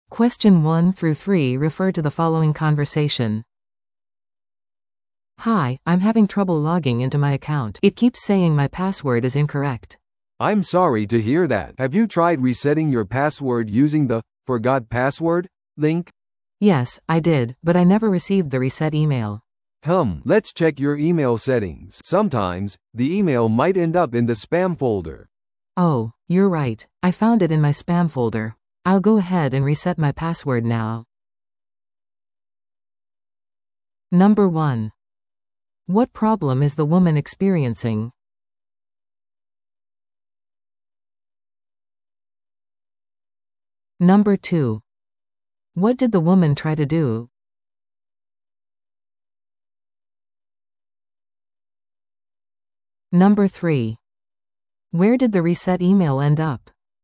PART3は二人以上の英語会話が流れ、それを聞き取り問題用紙に書かれている設問に回答する形式のリスニング問題です。
Technical support call